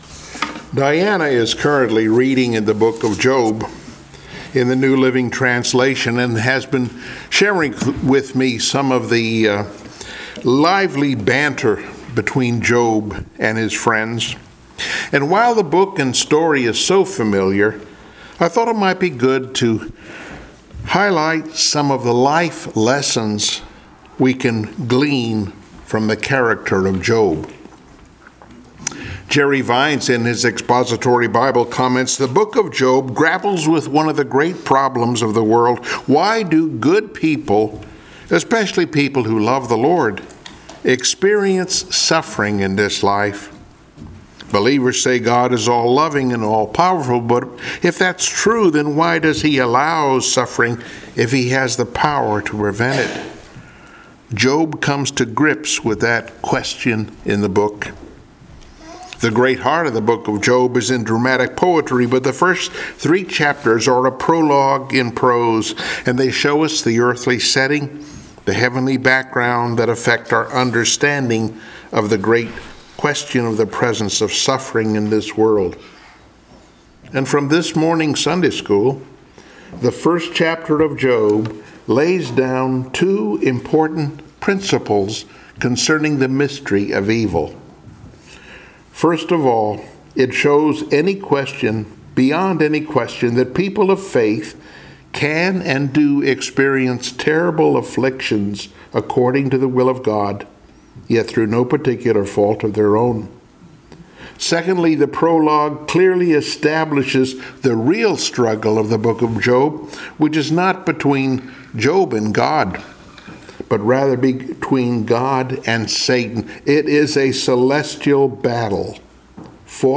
Passage: Job 1:1-3, 2:6-8, 40:1-4 Service Type: Sunday Morning Worship